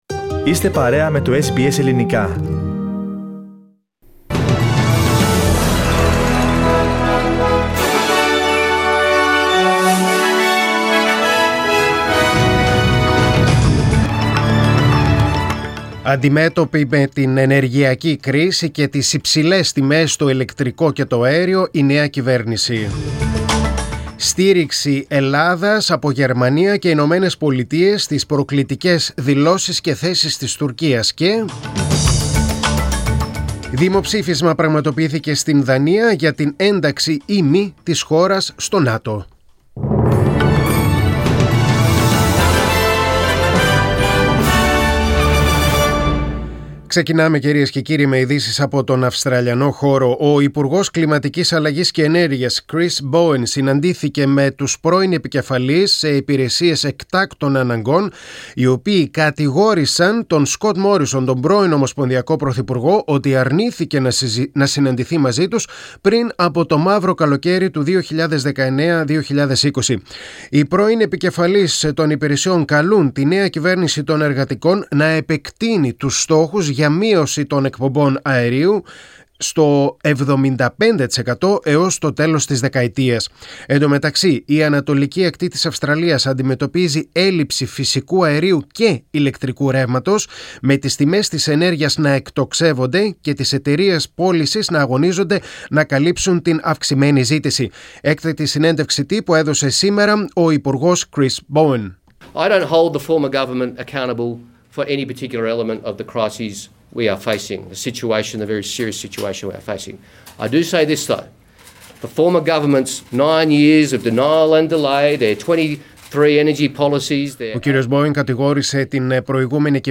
Δελτίο Ειδήσεων: Πέμπτη 2.6.2022